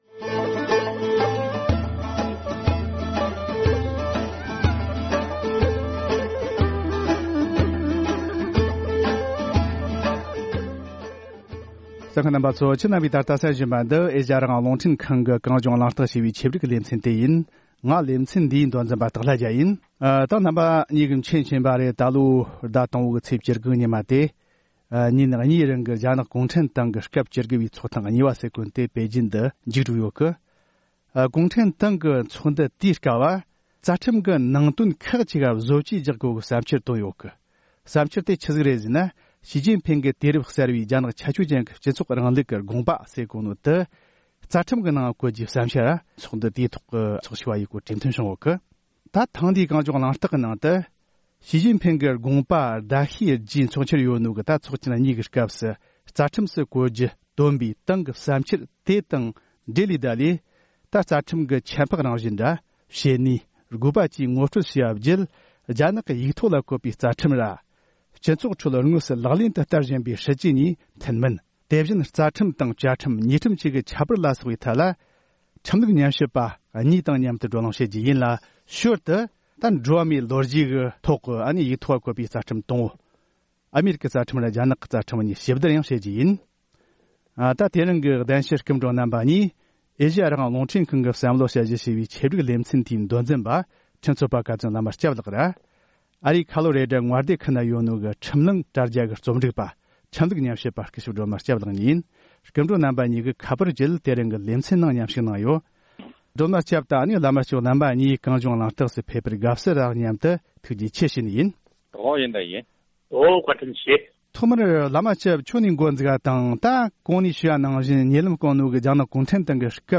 རྒྱ་ནག་གི་ཡིག་ཐོག་ལ་བཀོད་པའི་རྩ་ཁྲིམས་དང་སྤྱི་ཚོགས་ཁྲོད་དངོས་སུ་ལག་ལེན་དུ་བསྟར་བཞིན་པའི་སྲིད་ཇུས་གཉིས་མཐུན་མིན་ཐད་བགྲོ་གླེང༌།